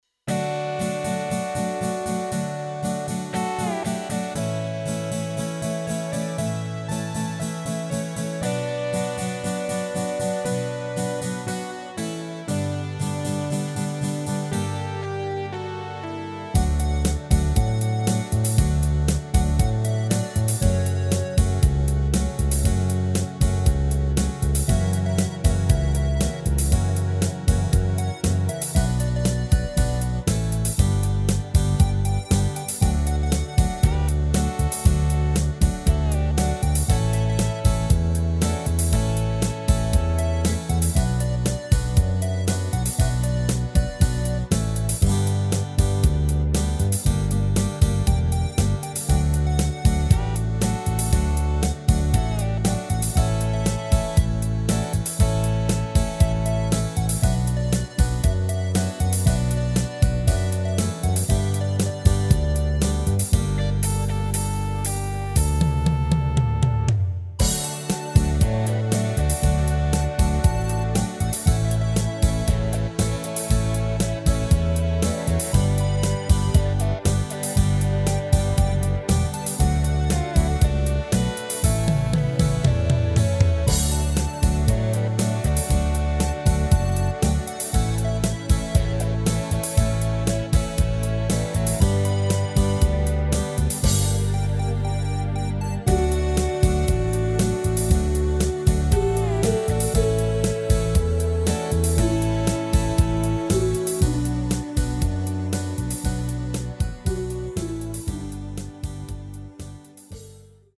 Rubrika: Pop, rock, beat
HUDEBNÍ PODKLADY V AUDIO A VIDEO SOUBORECH